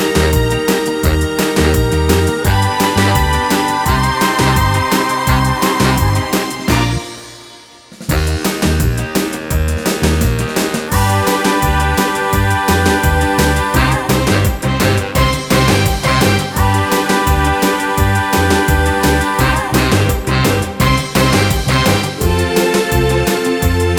Oldies (Female)